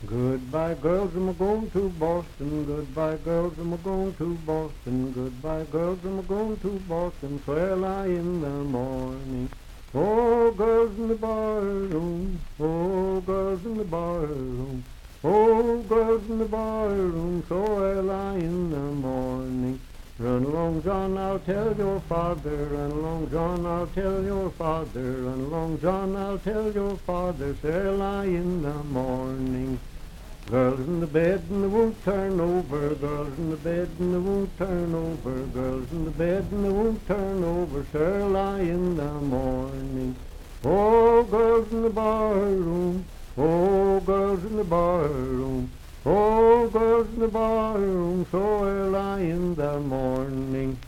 Unaccompanied vocal music
Dance, Game, and Party Songs
Voice (sung)
Franklin (Pendleton County, W. Va.), Pendleton County (W. Va.)